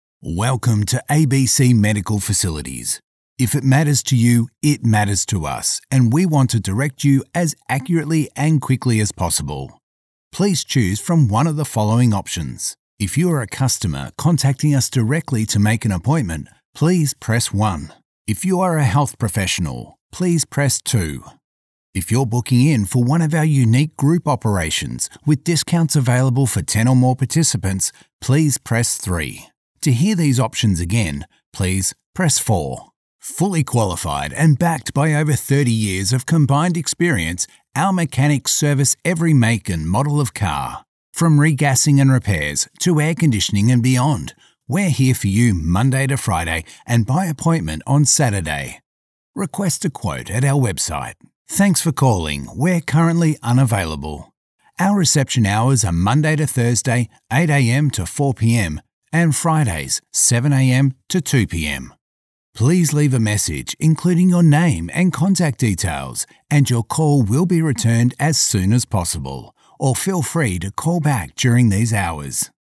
On Hold Message
English - Australian
Middle Aged